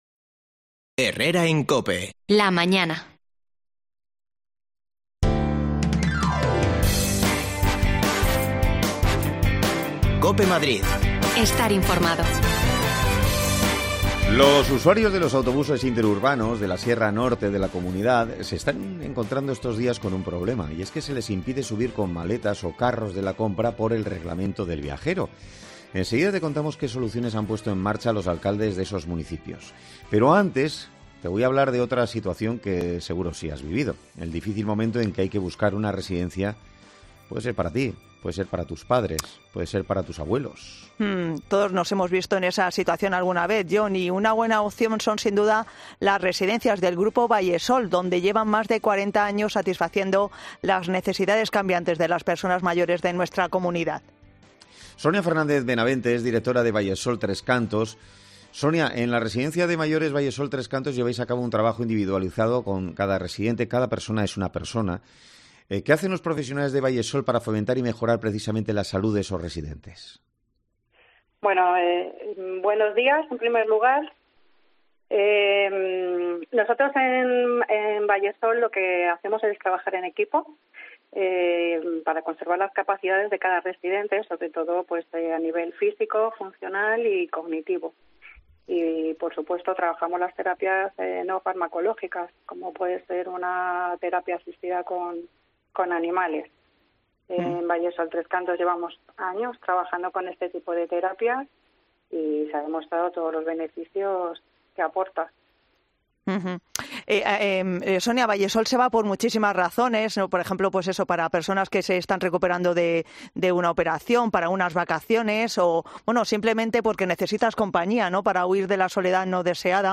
desconexiones locales de Madrid